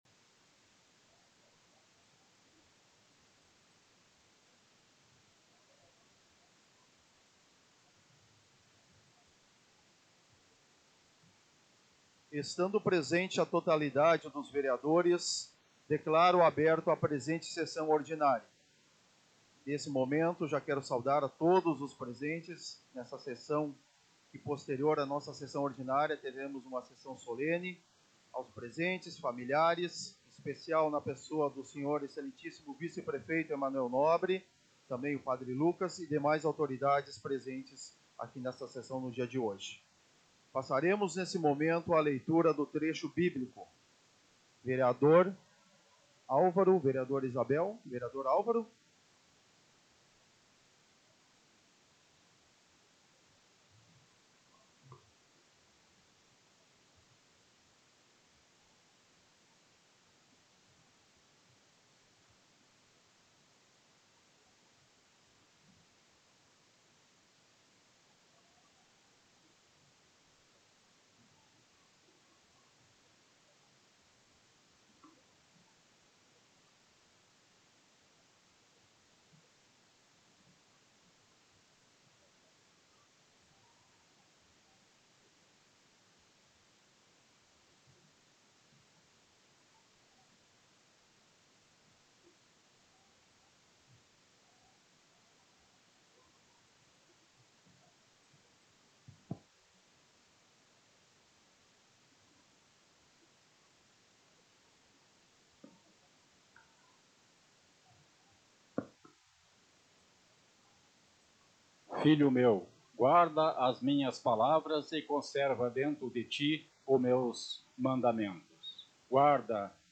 Áudio Sessão 31.03.2025